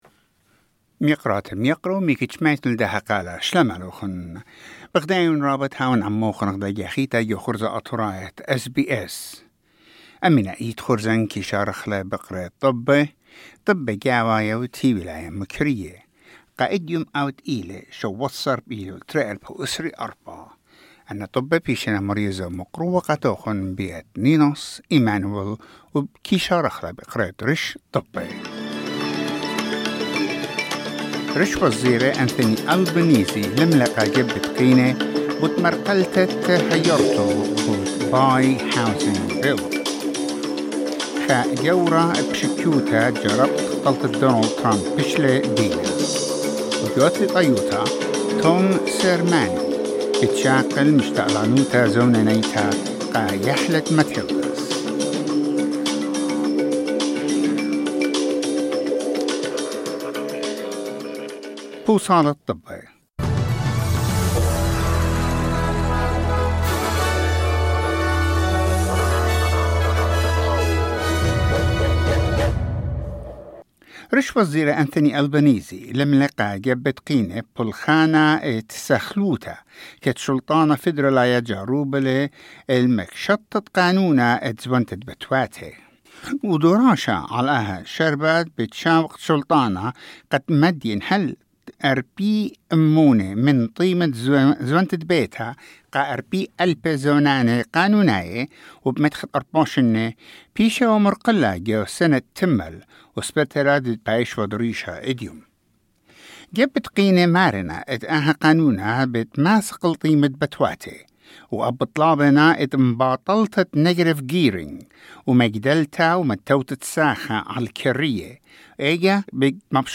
Assyrian news bulletin: 17 September 2024